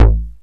RNDMOOG2.wav